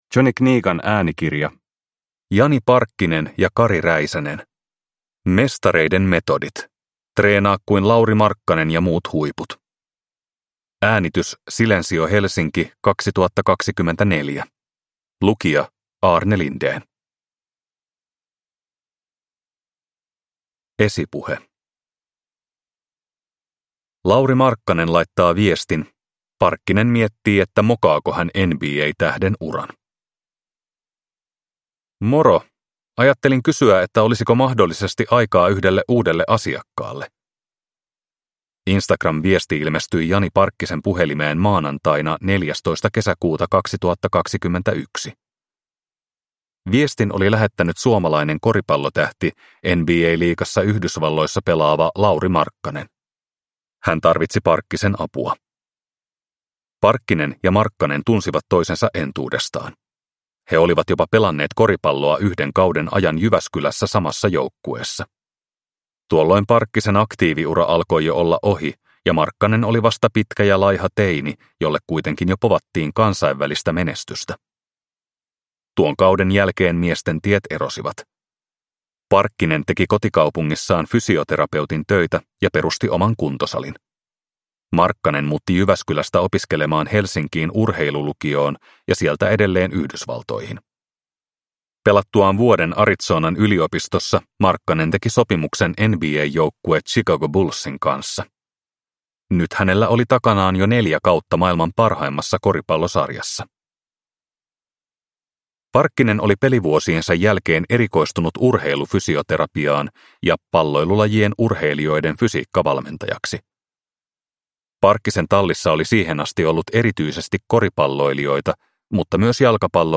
Mestareiden metodit – Ljudbok